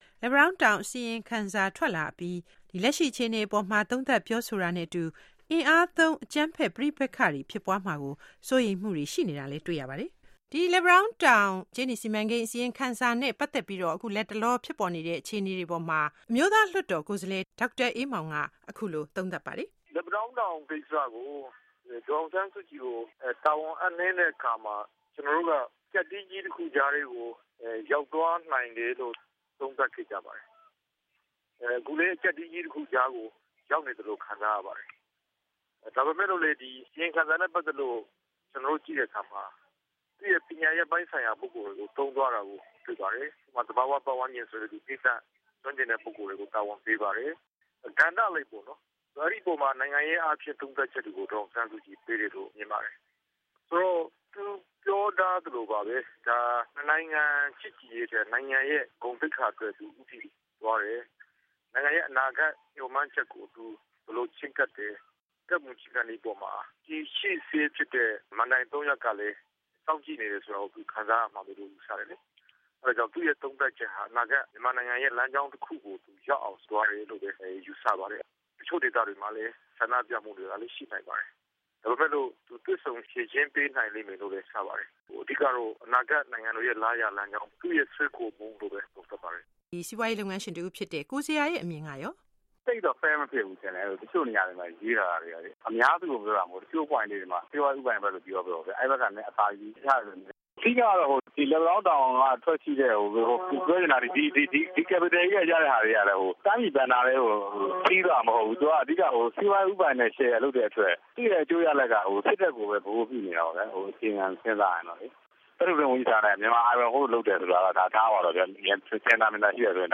လက်ပံတောင်းတောင် VOXPOX